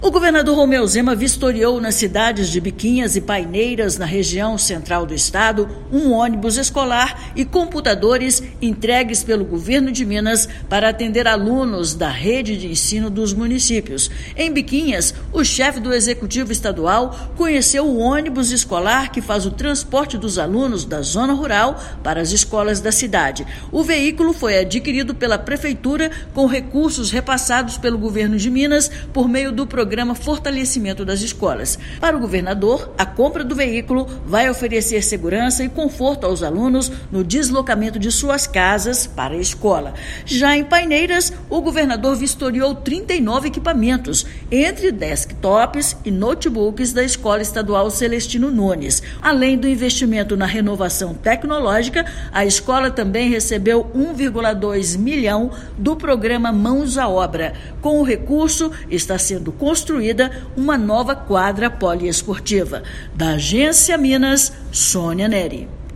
[RÁDIO] Governador vistoria entregas para a educação na região Central do estado
Em Biquinhas, Zema conheceu ônibus escolar adquirido com recursos do Estado; já em Paineiras, conferiu os novos equipamentos de informática entregues pelo Governo de Minas. Ouça matéria de rádio.